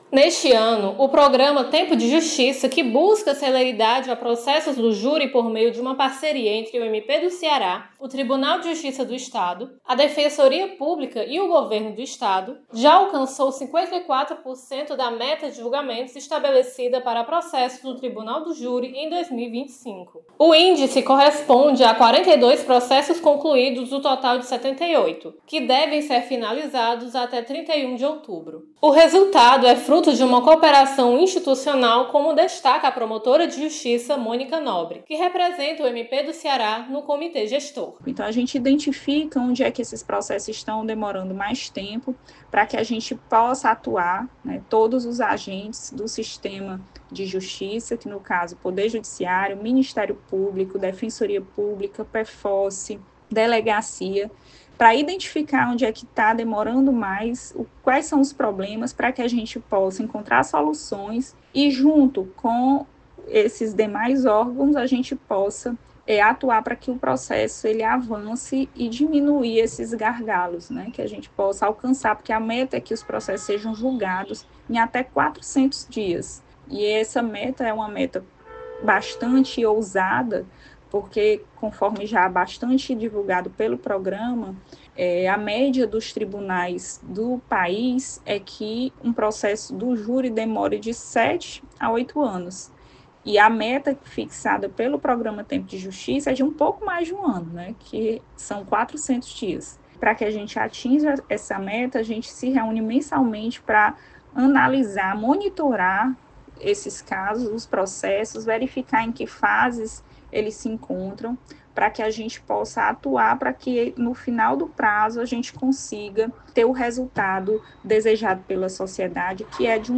MP do Ceará sedia reunião do Programa Tempo de Justiça e destaca alcance de 54% da meta de julgamentos em 2025 Repórter